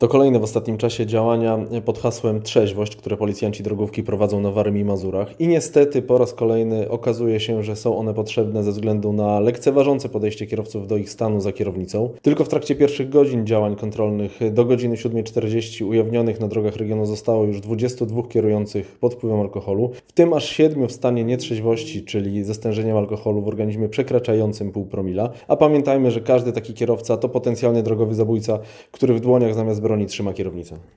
Mówił Radiu 5